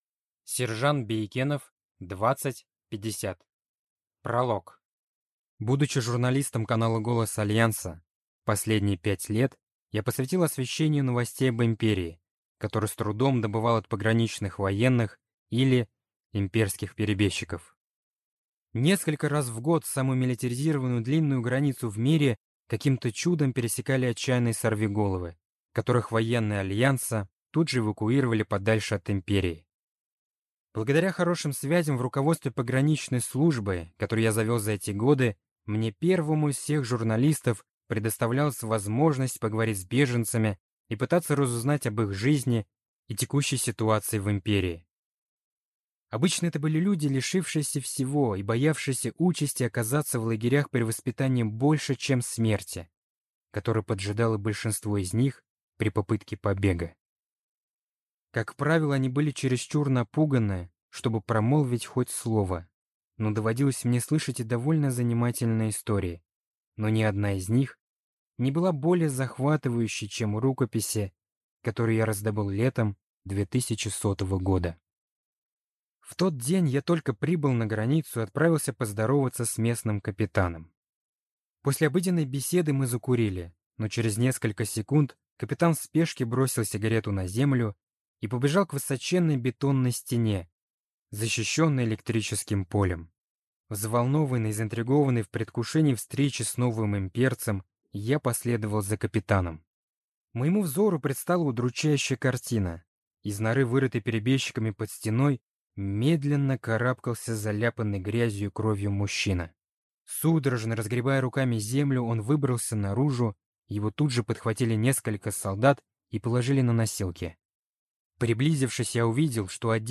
Аудиокнига 2050 | Библиотека аудиокниг